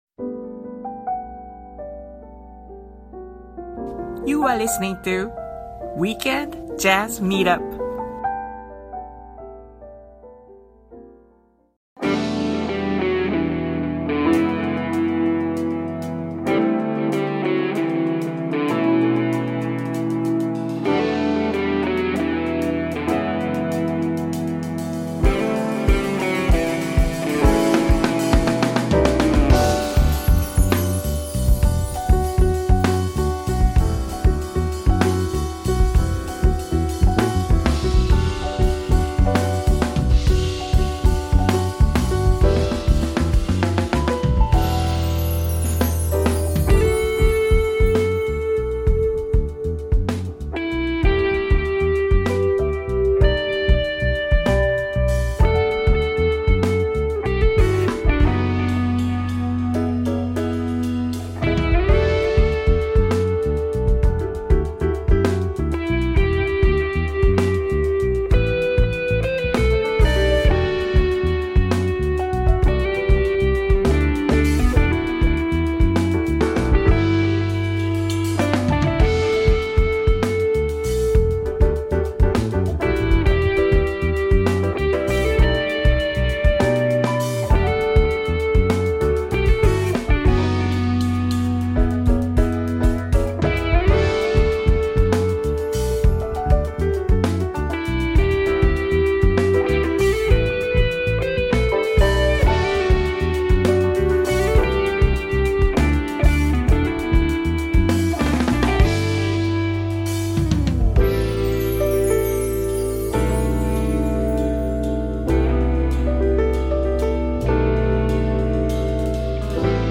“Weekend Jazz Meetup” and “９匹の羊” are weekly music promotional programs dedicated to Japanese jazz artists, organized by a fellow Japanese artist.